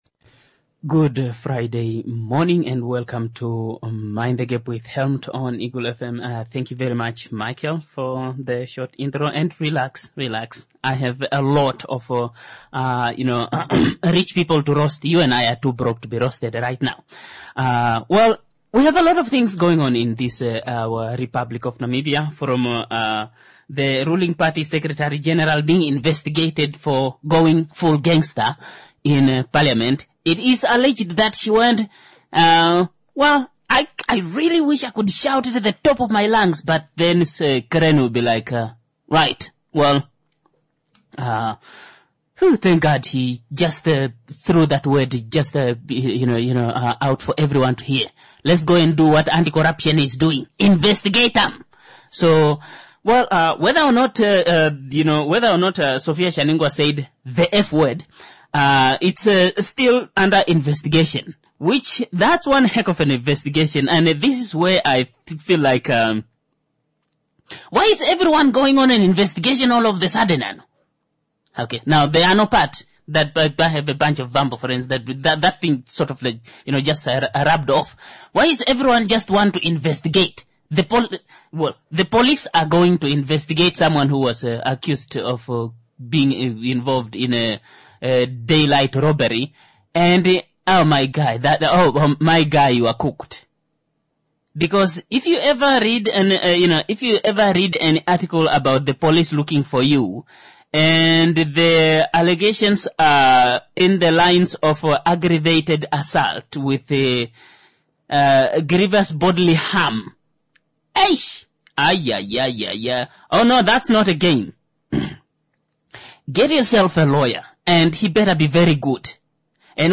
⚠ This is a COMEDY show.